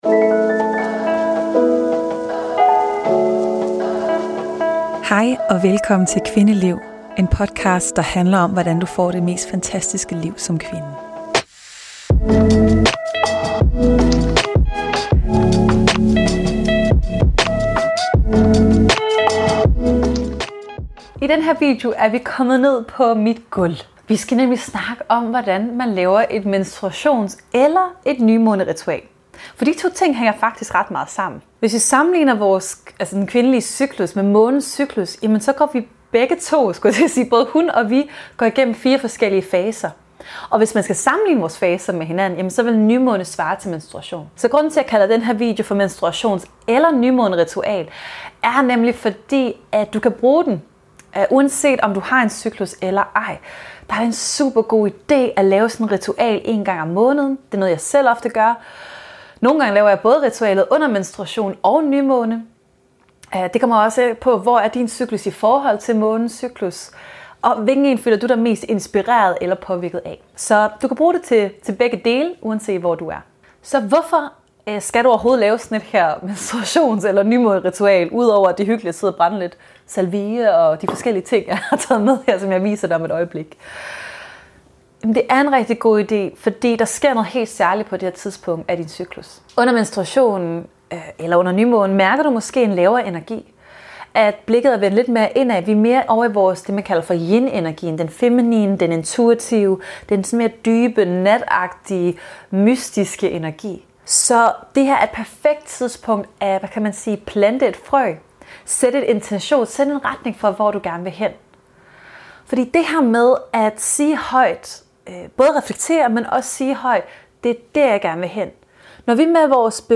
OBS: Afsnittet inkluderer en guidet meditation - så brug den gerne under din menstruation eller nymånen.